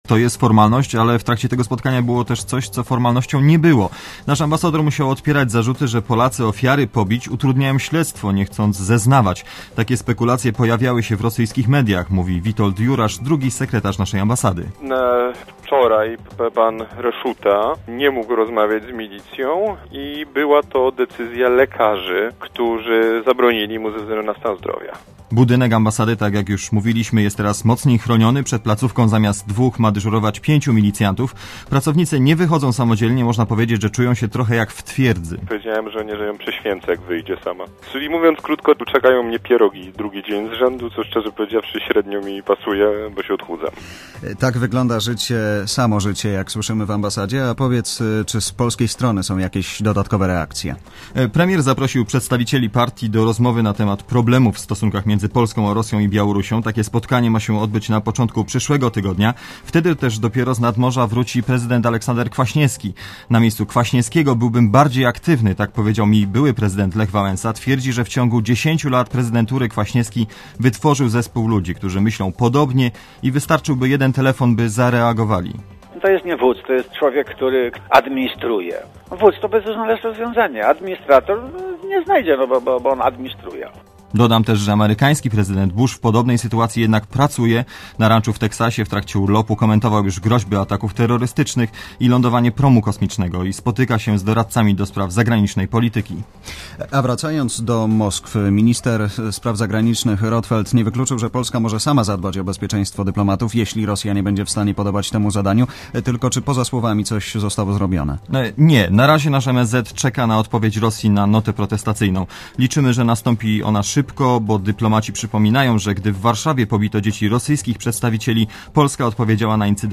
reportera radia ZET*